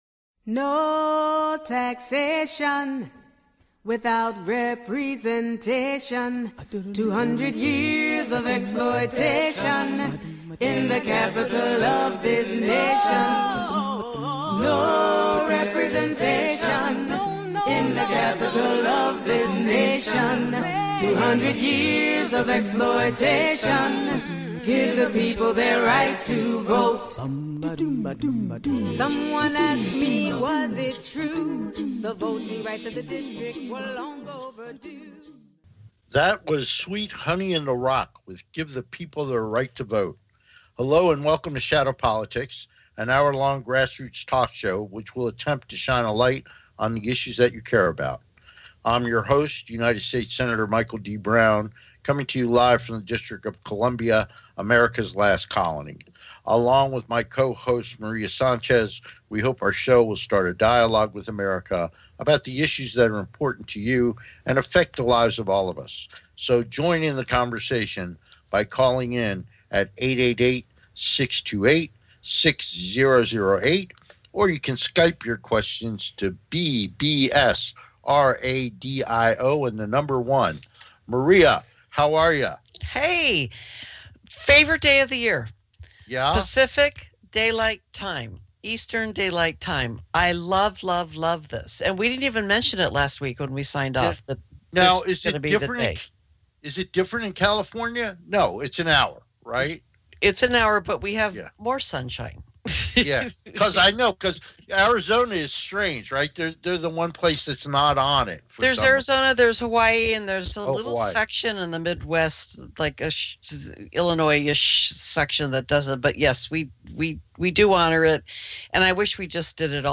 Talk Show Episode, Audio Podcast, Shadow Politics and Guests - WE THE PEOPLE FOR SENSIBLE GUN LAWS on , show guests , about gun regulations,Universal Background Checks,oppose Deregulation on Sales of Gun Silencers,NRA,ban Sale of Bump Stocks, categorized as Health & Lifestyle,History,Kids & Family,News,Politics & Government,Self Help,Society and Culture,Variety
Shadow Politics is a grass roots talk show giving a voice to the voiceless.